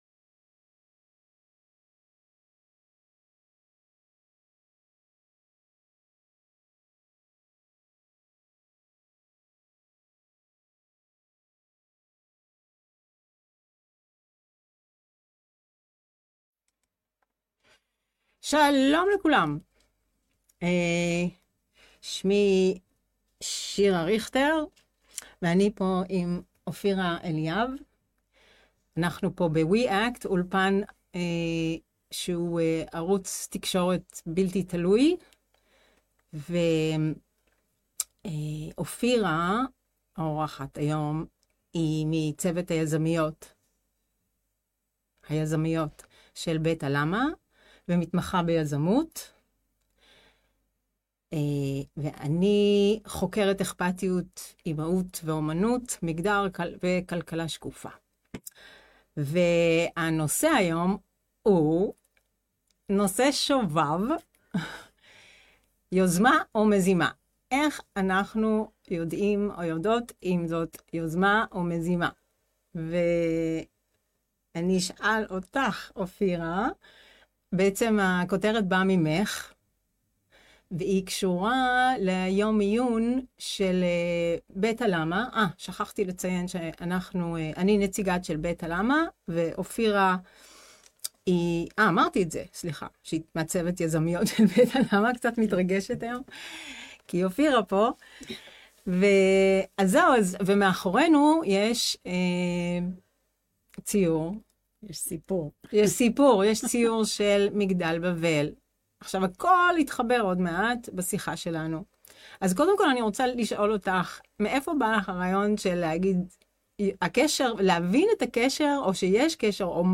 יוזמה או מזימה, איך יודעות/ים? בתקופה שבה הרבה יוזמות מתבררות כמזימות (ולהיפך), איך יודעות/ים לזהות מה עומד בפנינו? שיחה מלאת תהיות מרתקות עם חברת צוות בית הלמה, מתמחה בתחומי ...